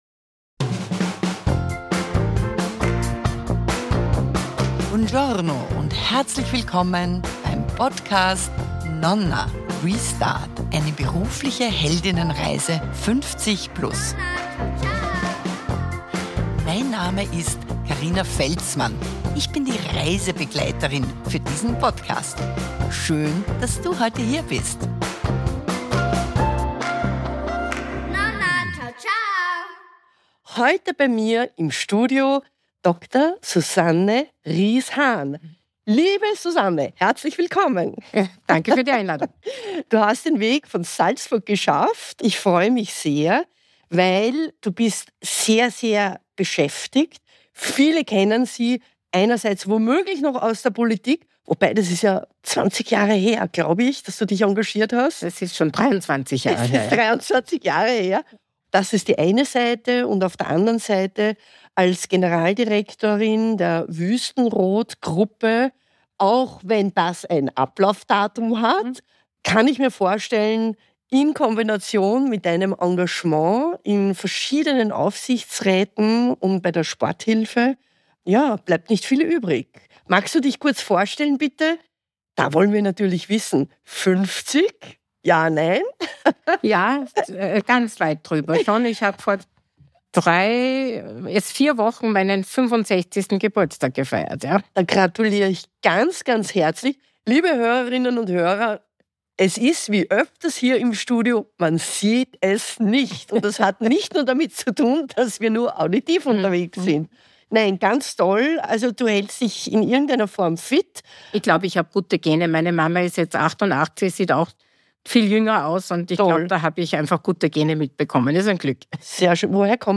Als erste Vizekanzlerin Österreichs kämpfte sie mit Vorurteilen – vom "Fräulein" zum Spitznamen "Königskobra". Im Interview hörst du, warum sie mit 40 die Politik bewusst verlassen hat, wie der Wechsel in die Wirtschaft zustande kam und was Führung für sie wirklich bedeutet.